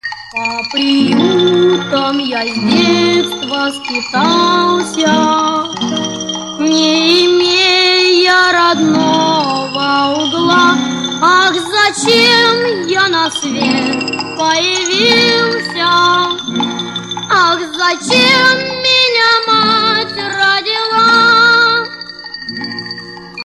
• Качество: 128, Stereo
печальные
Печальная песня сироты о маме